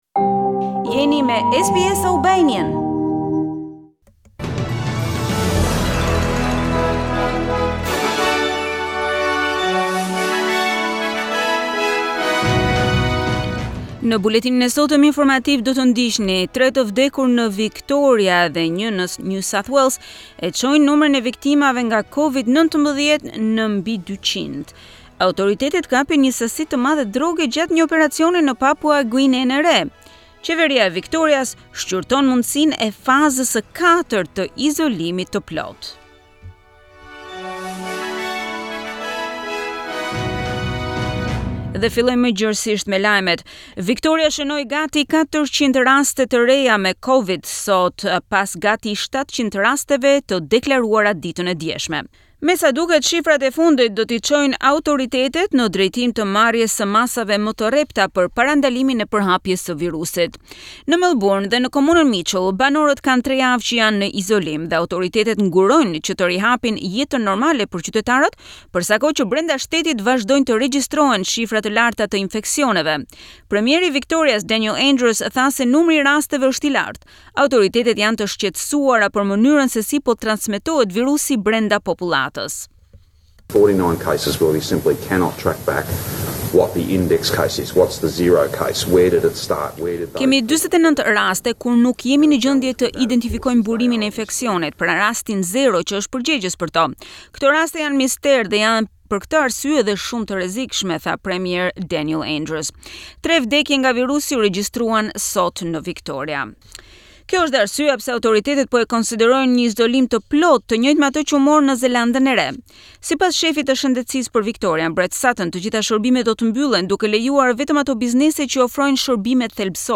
SBS News Bulletin - 1 August 2020